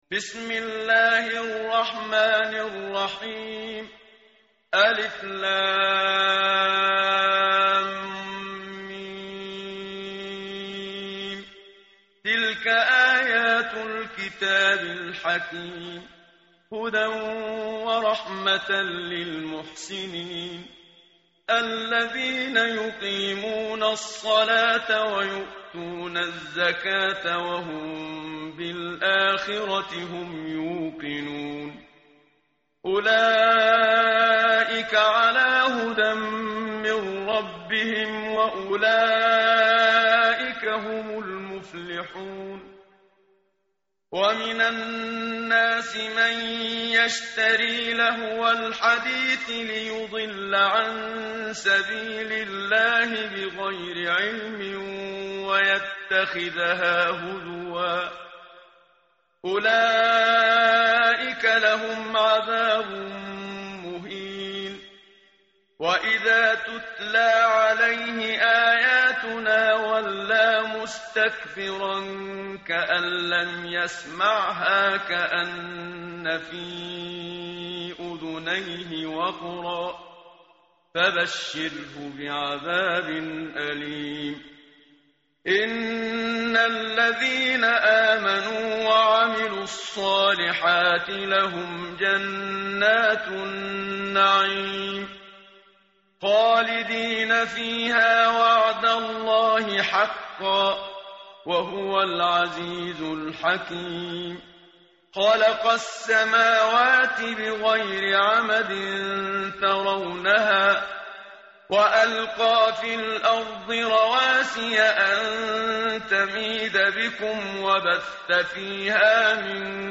متن قرآن همراه باتلاوت قرآن و ترجمه
tartil_menshavi_page_411.mp3